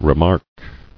[re·mark]